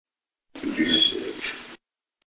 However, here is one EVP that was captured during the filming while in the underground tunnel that
We first thought this was a German voice saying the word "Abusage"